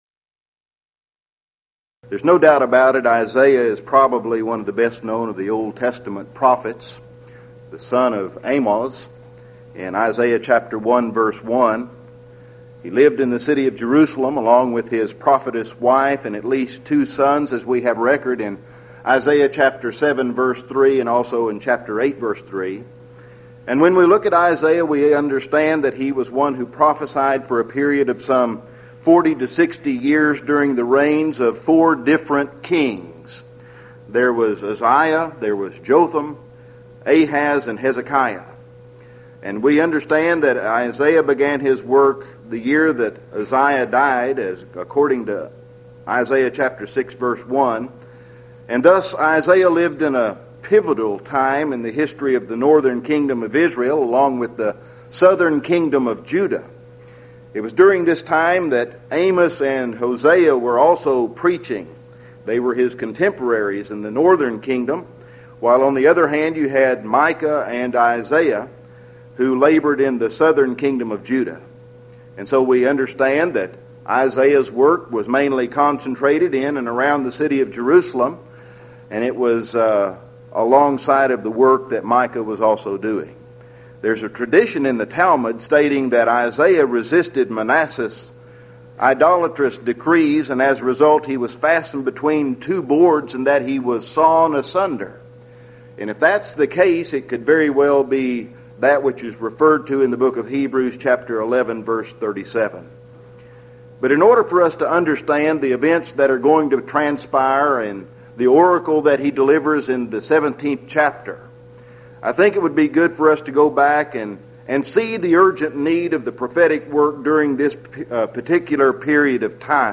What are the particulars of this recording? Houston College of the Bible Lectures Event: 1995 HCB Lectures